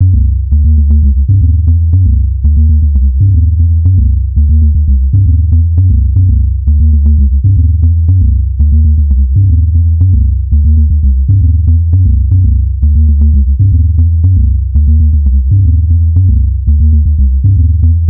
• fat groovy bass sequence 117 bpm.wav
fat_groovy_bass_sequence_117_bpm_UIU.wav